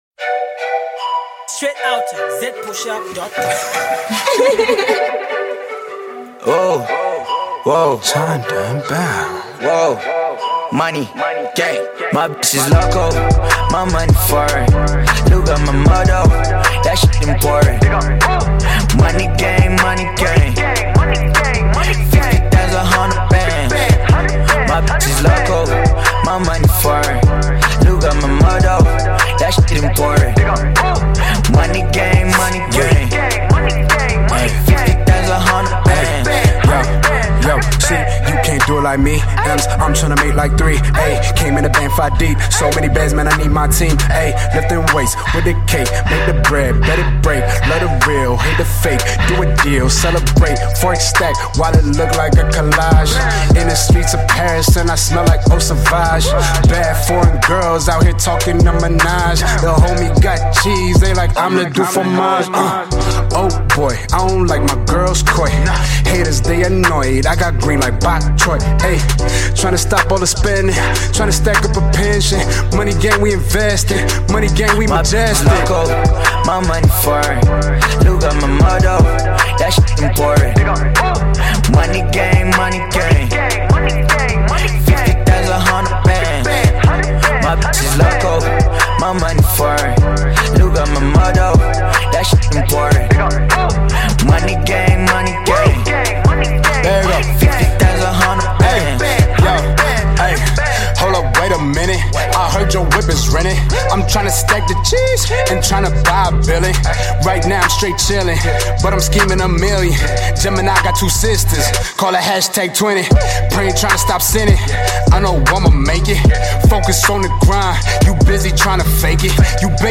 Zambian top celebrated hip-hop lord